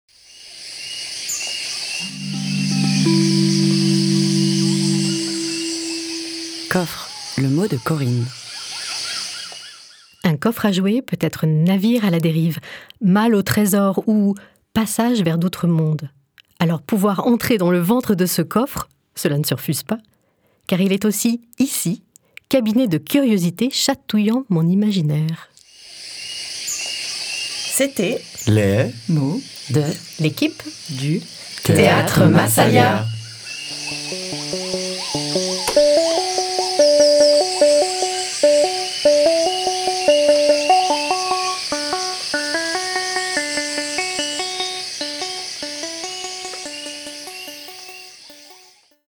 Le mot de l'équipe